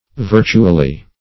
Virtually \Vir"tu*al*ly\, adv.